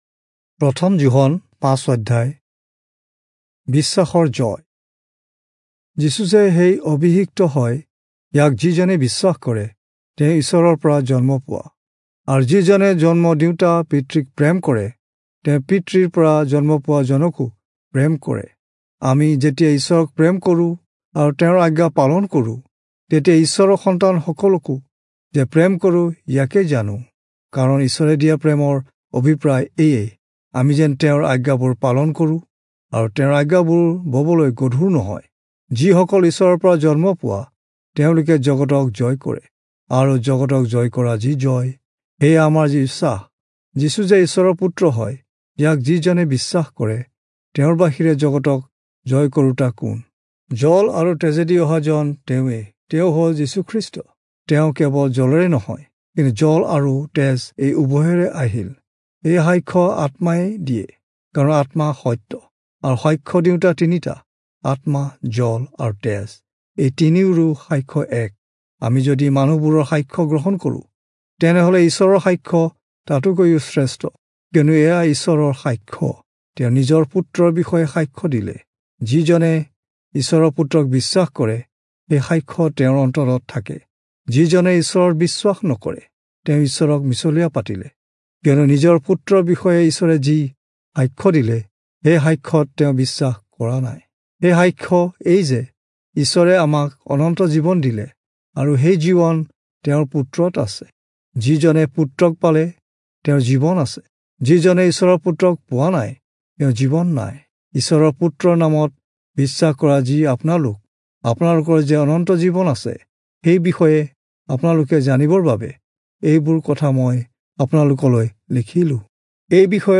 Assamese Audio Bible - 1-John 5 in Tov bible version